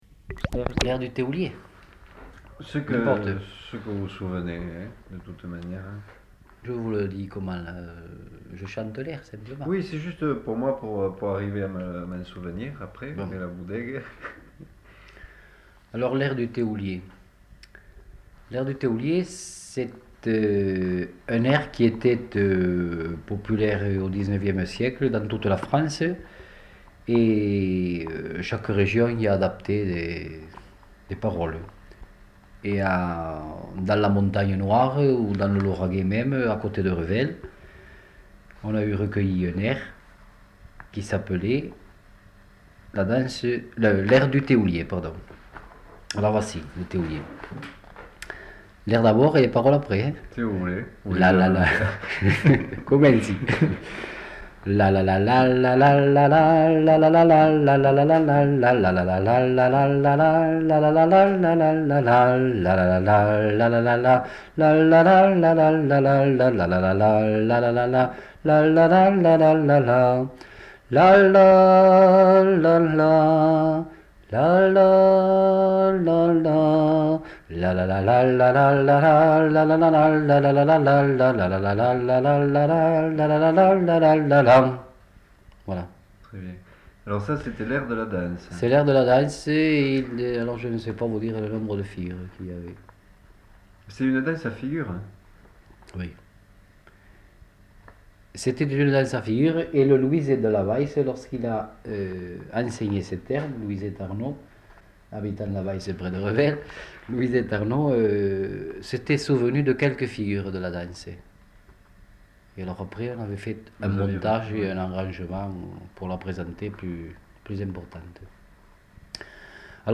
Aire culturelle : Lauragais
Genre : chant
Effectif : 1
Type de voix : voix d'homme
Production du son : fredonné ; chanté